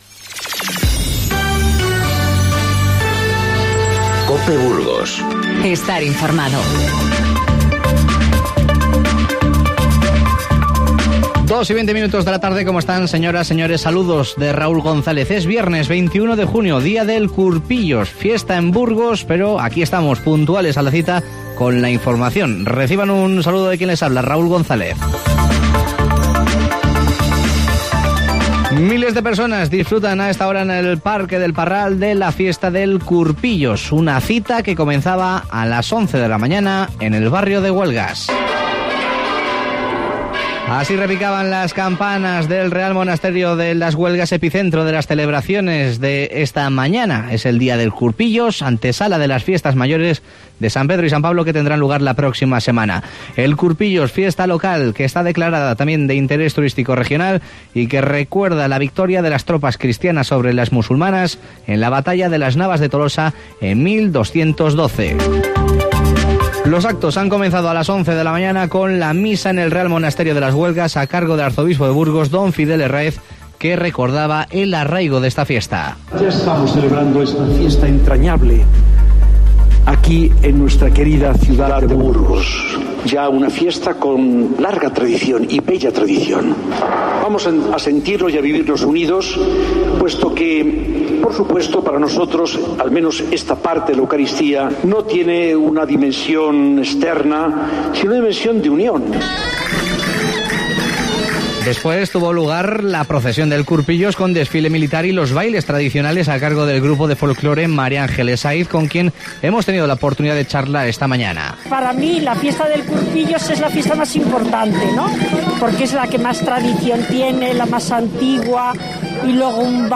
Informativo Mediodía COPE Burgos 21/06/19